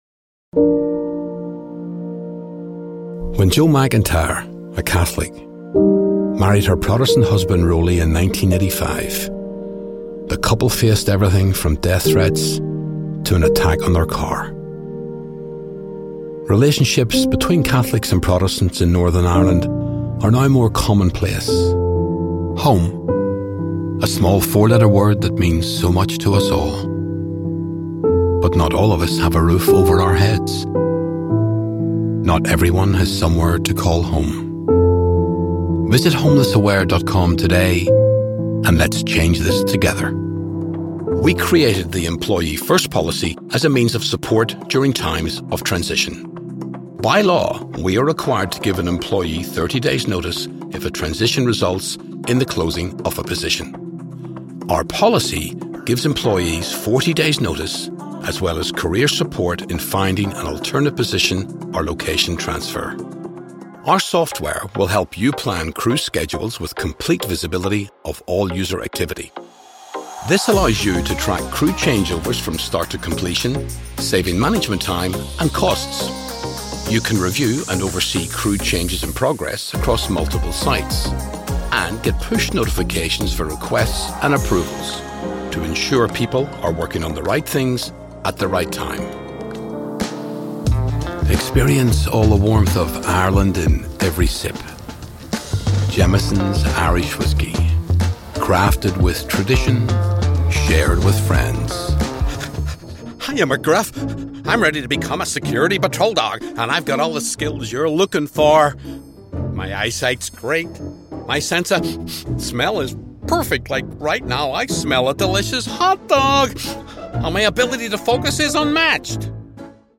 Compilation Reel
Northern Ireland - English/European - Southern Ireland - Broken Italian/English - Scottish - English Variations - American Variations -
Young Adult
Middle Aged
I also work from my broadcast approved home studio.